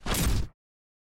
Troll attack.mp3